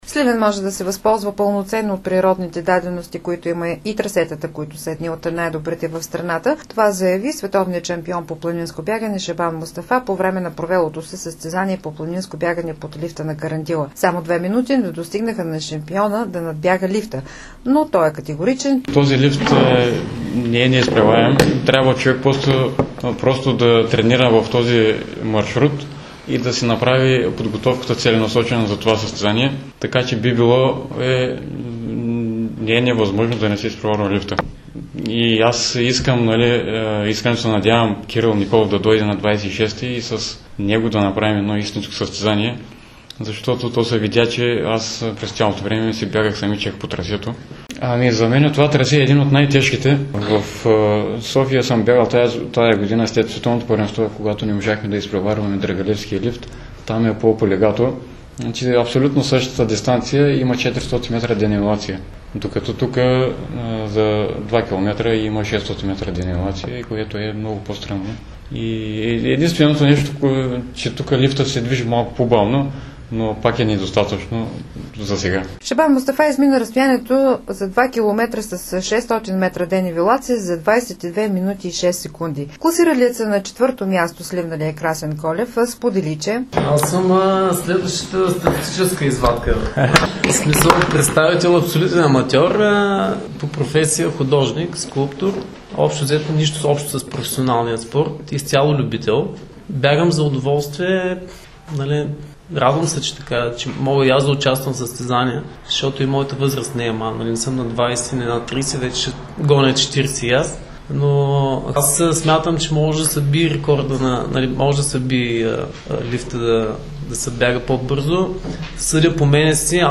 Как - чуйте в следващия репортаж: